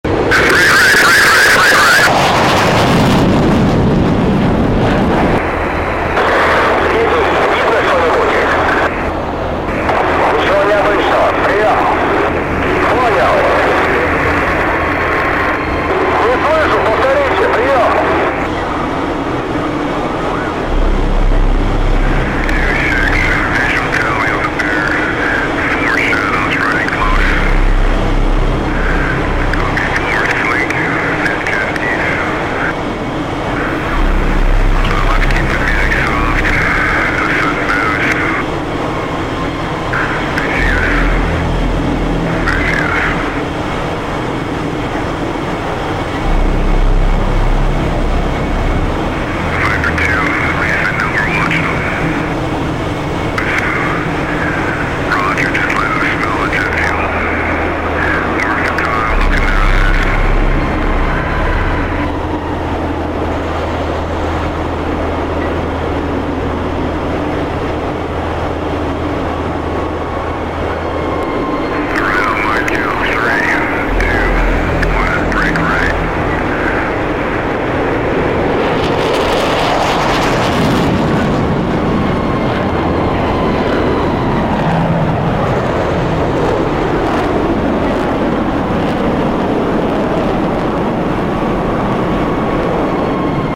US F 22 fighter jets encounter sound effects free download